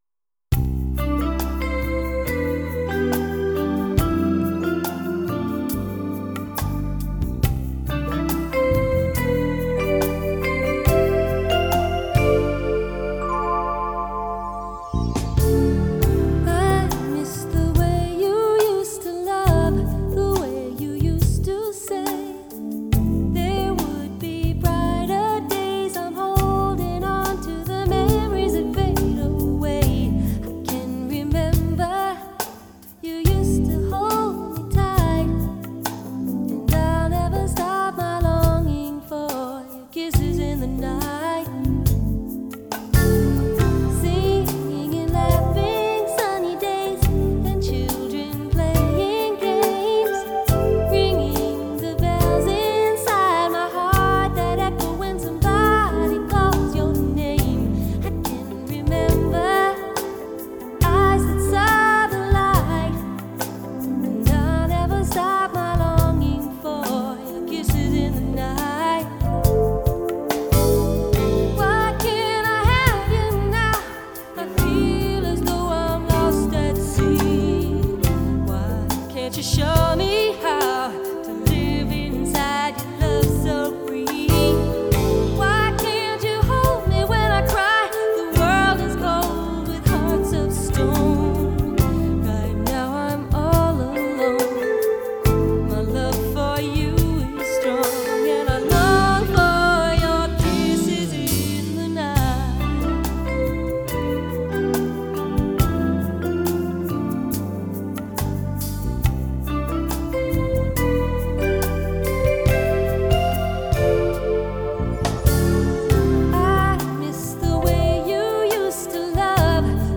Keyboards
Drums
Tenor Sax Solo